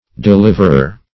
Deliverer \De*liv"er*er\, n.